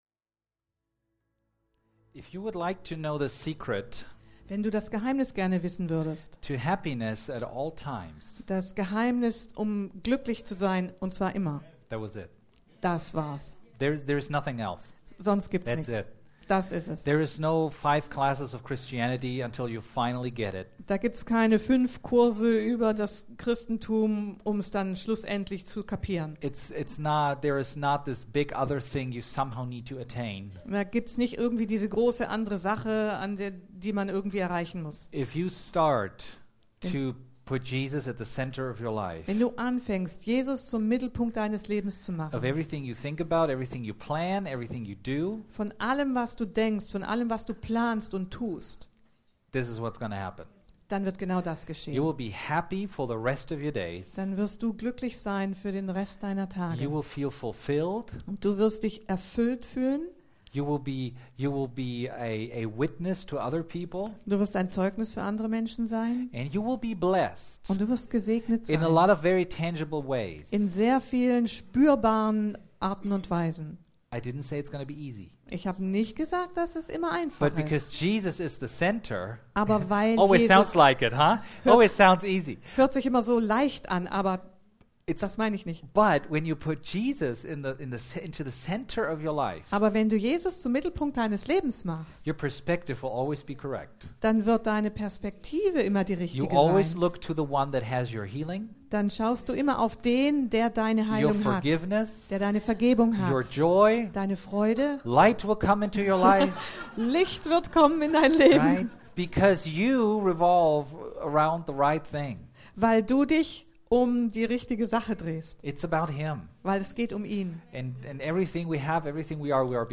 2019 Sermons / Predigten | Rhema Bibel Gemeinde Podcasts | Page 3